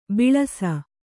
♪ biḷasa